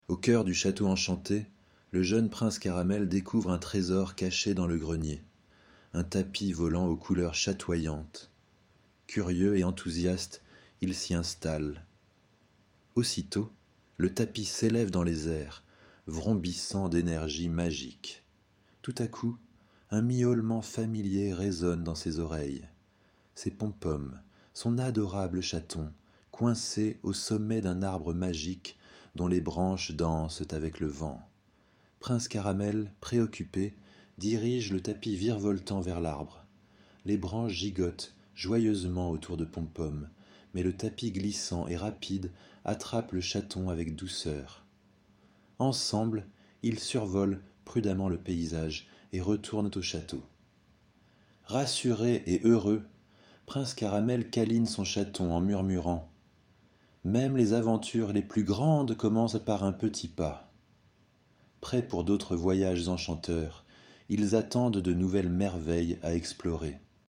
🎧 Lecture audio générée par IA